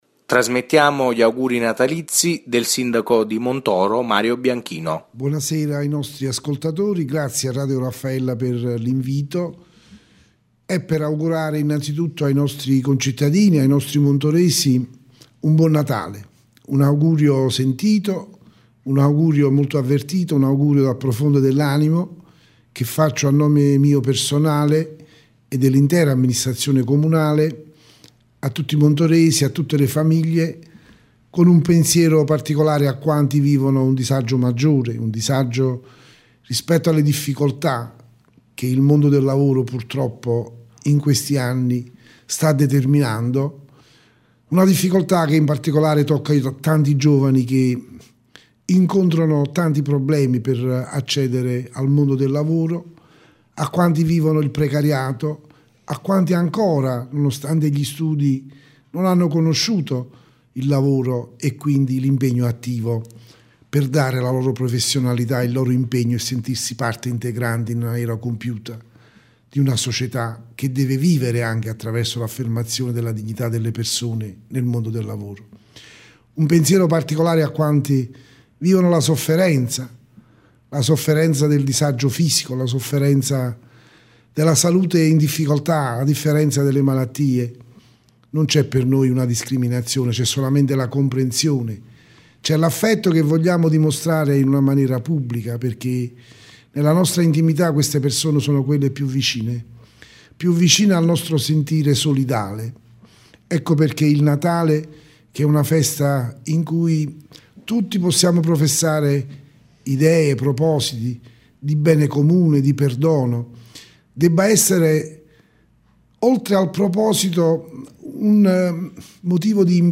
Il Sindaco di Montoro Bianchino, il Capogruppo di Maggioranza Lepre ed il Sindaco di Solofra Vignola, attraverso i nostri microfoni hanno augurato buone feste ai cittadini delle rispettive comunità.
Gli auguri del Sindaco di Montoro, Bianchino:
Auguri-Natalizi-del-Sindaco-di-Montoro-Bianchino-2015.mp3